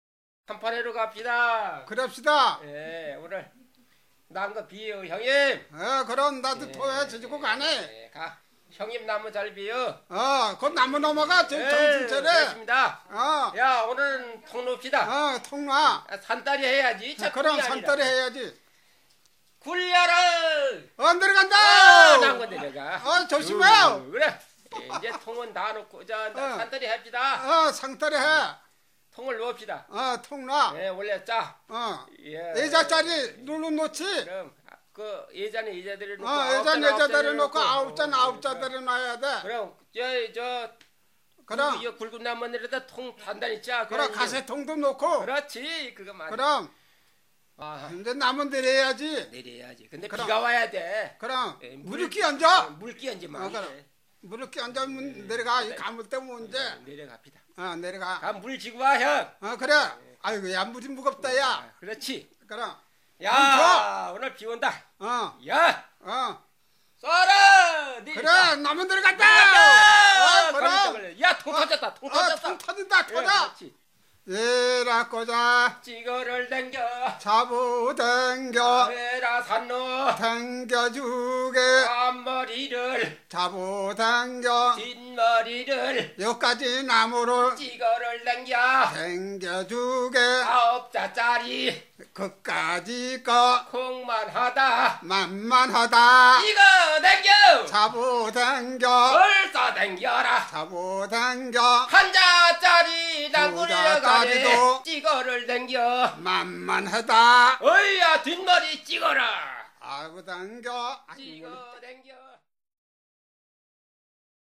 鉄原地つき歌とその他の仕事歌 - 江原道地域の地つき歌はその名のとおり家を建てるために礎を置く場所を中心に土台を固めながら歌う集団仕事歌であり、 江原道では ‘チジョン(チギョン)ソリ’とも言われる。